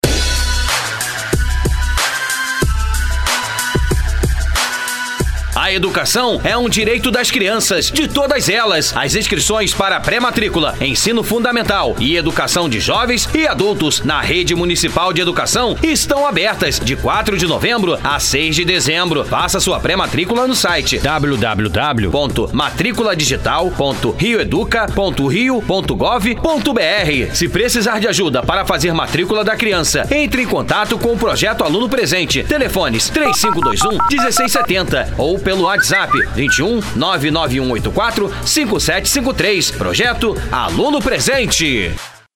Spot de matrícula